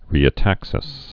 (rēə-tăksĭs)